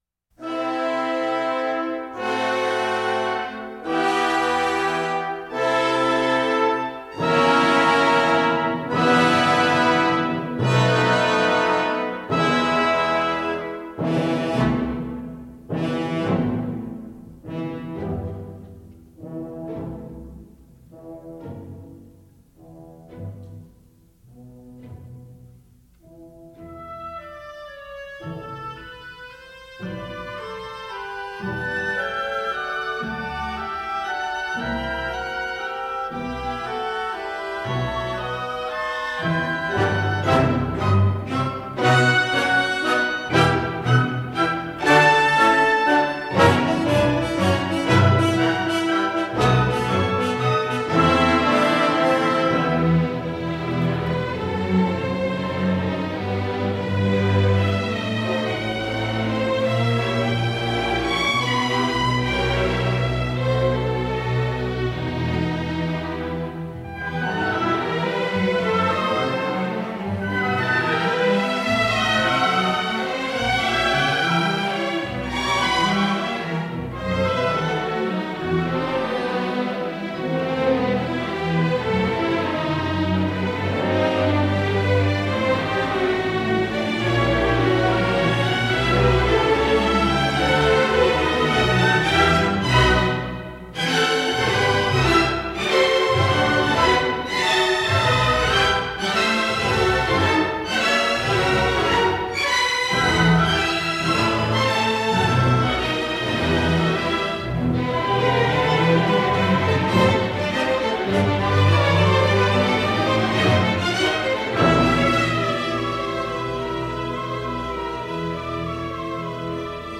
GenereMusica Classica / Sinfonica
NoteRegistrazione in studio, analogica